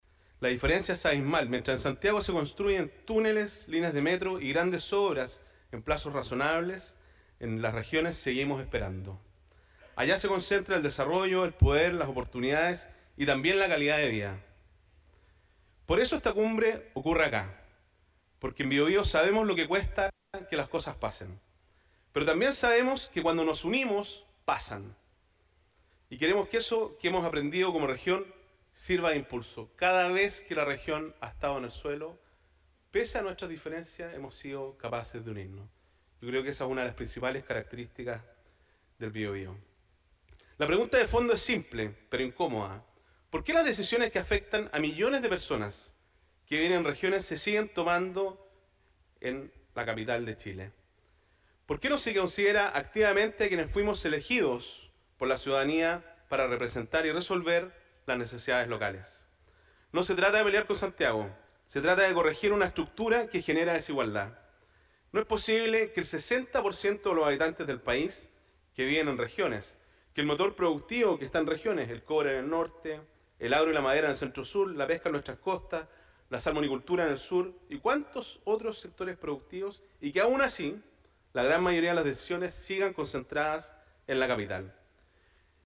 “Descentralización para avanzar” fue el lema de la Cumbre de las Regiones 2025, realizada este lunes en el Teatro Biobío y organizada conjuntamente por Corbiobío, el Gobierno Regional, Desarrolla Biobío y la Asociación de Gobernadores y Gobernadoras Regionales de Chile (Agorechi).